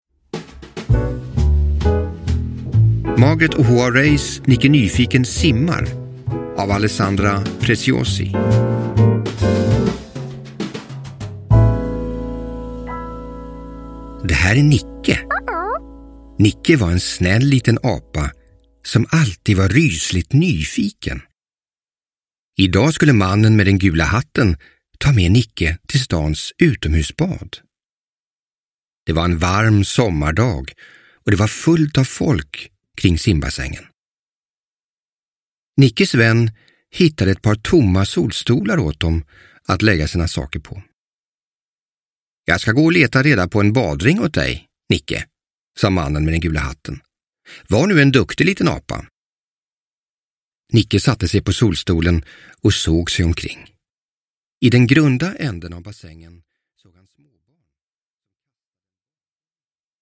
Nicke Nyfiken simmar – Ljudbok – Laddas ner